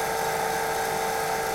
money_in_loop.wav